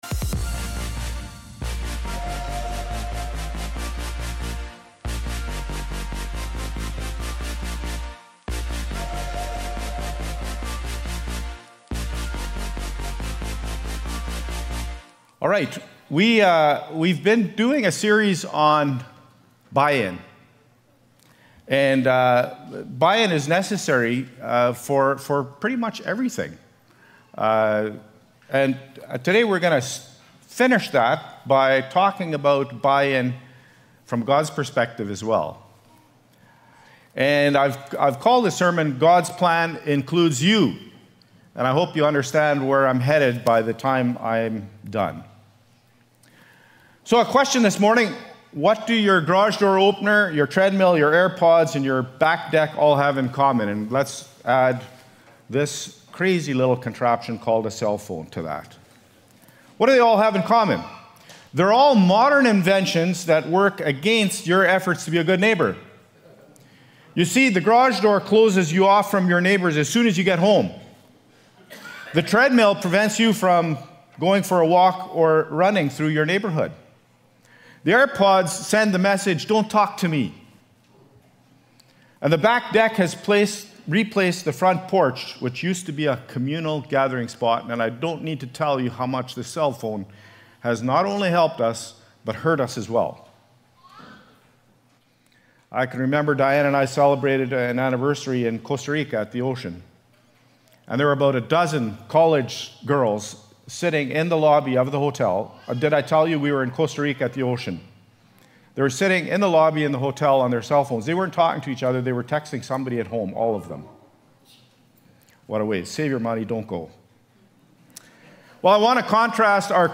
Nov-24-Worship-Service.mp3